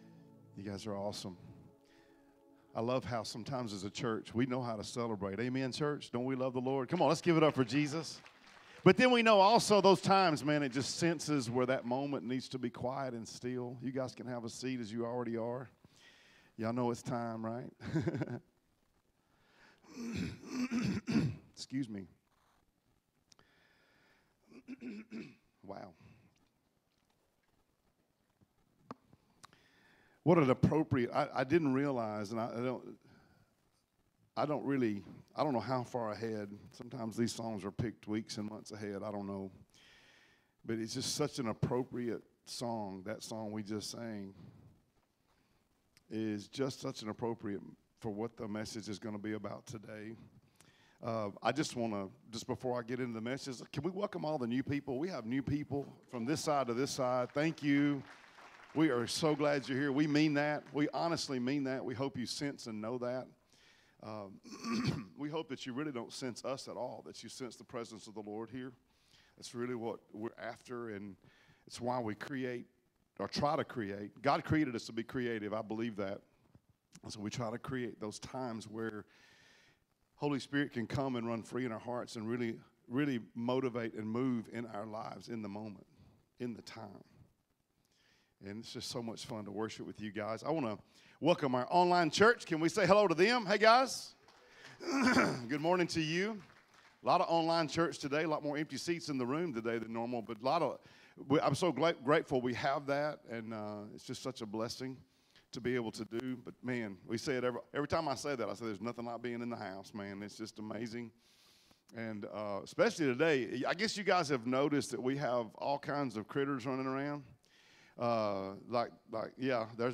Sermons | Discovery Church